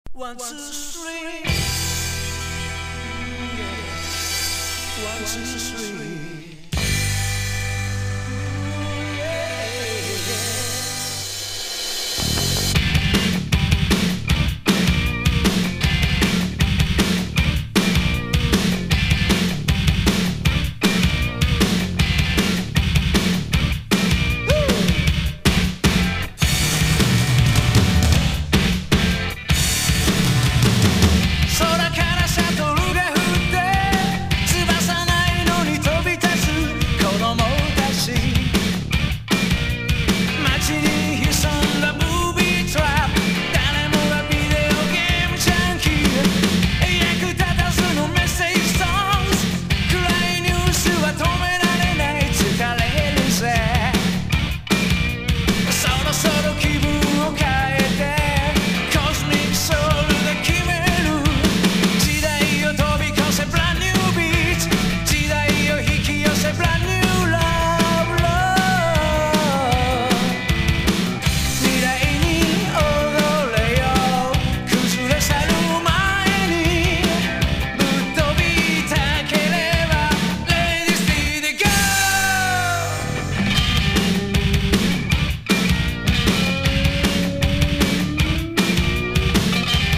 勢いのあるファンキーなナンバー!!
# 60-80’S ROCK# PUNK / HARDCORE